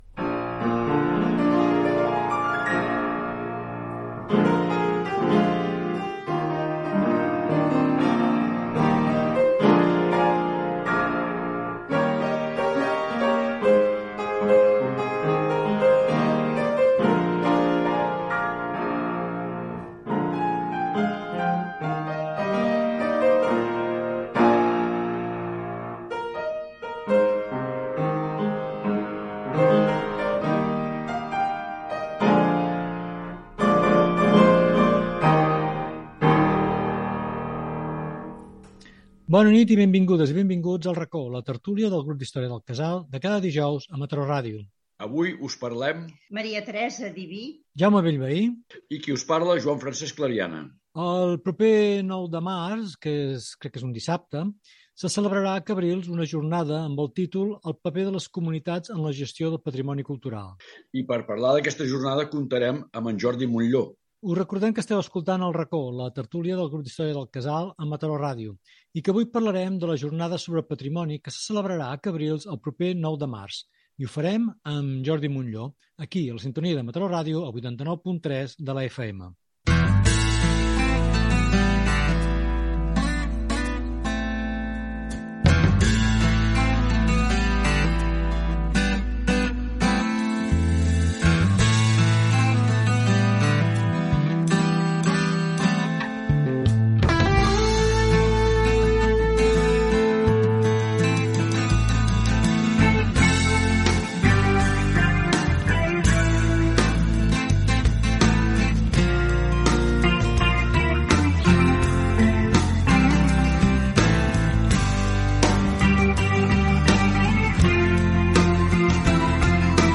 Sintonia, presentació, sumari i inici de la tertúlia del grup d'història del Casal de Mataró.